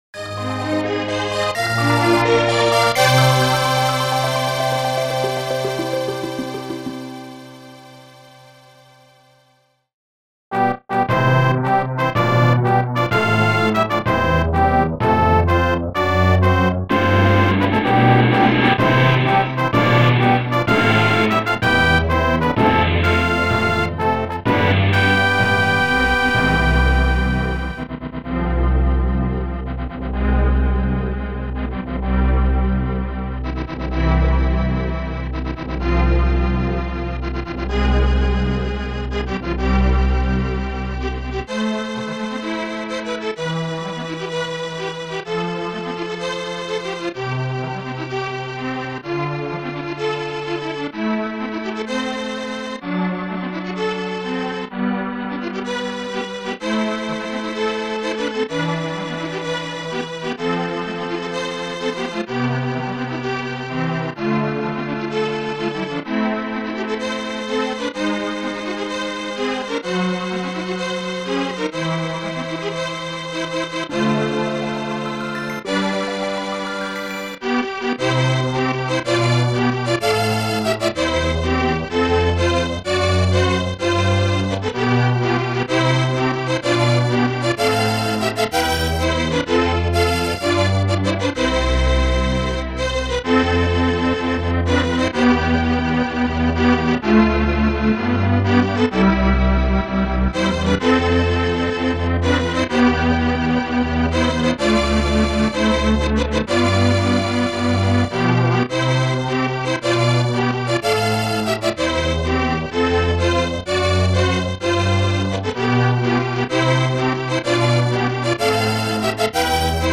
SPC-to-MP3 conversion